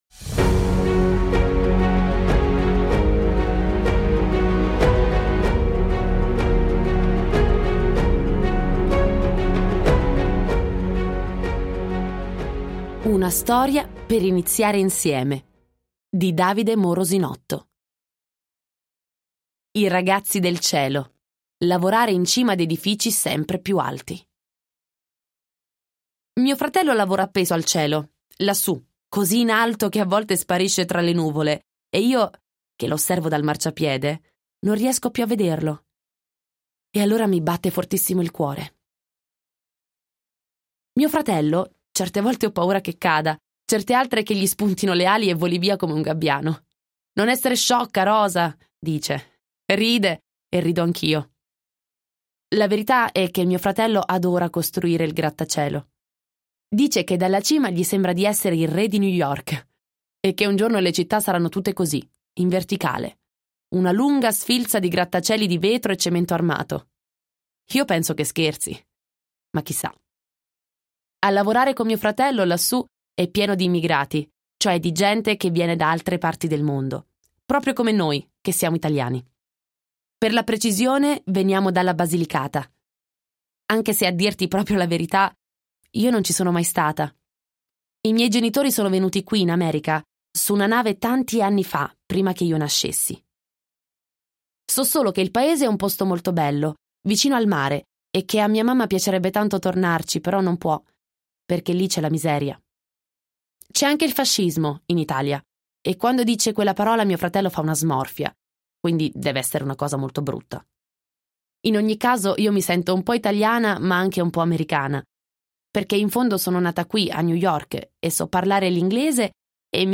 Le narrazioni, tutte speakerate da professionisti, possono essere ascoltate in classe o assegnate per compito a casa.